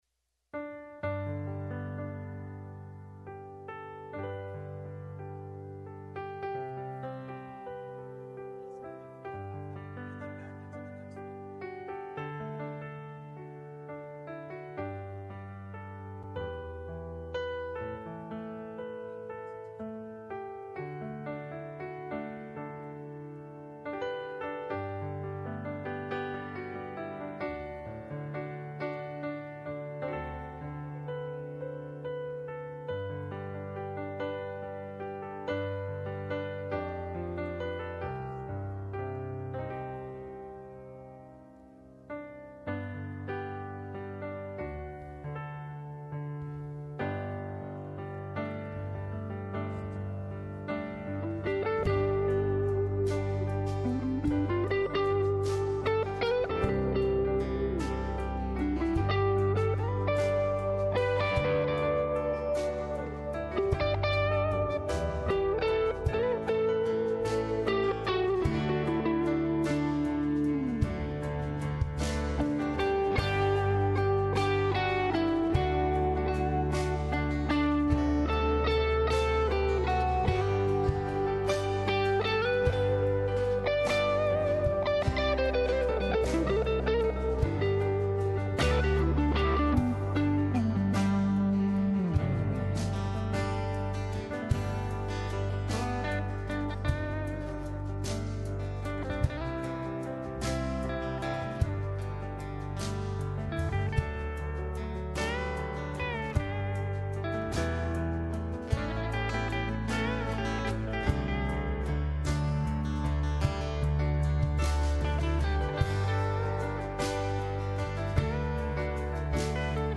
Westgate Chapel Sermons A Praying Church - Choose A Better Portion Nov 15 2020 | 01:30:50 1x 00:00 / 01:30:50 Subscribe Share Apple Podcasts Overcast RSS Feed Share Link Embed